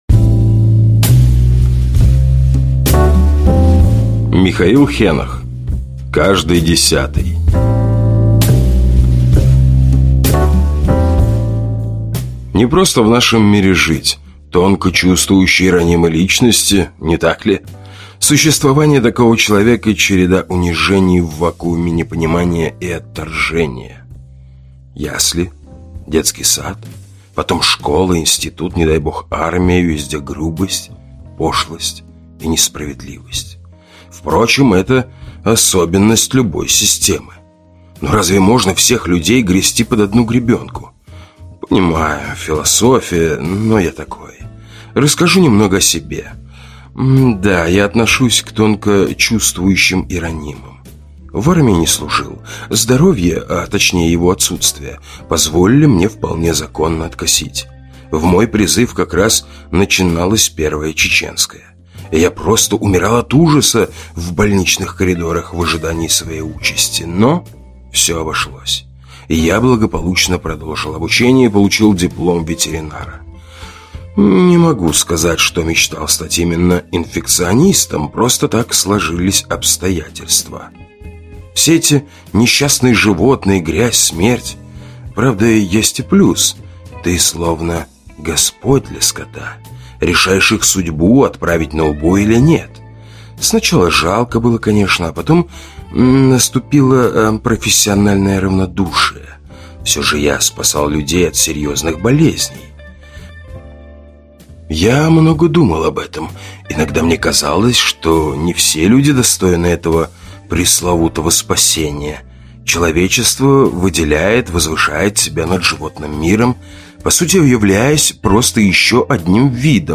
Аудиокнига Извне | Библиотека аудиокниг